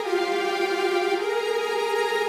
Index of /musicradar/gangster-sting-samples/105bpm Loops
GS_Viols_105-GBb.wav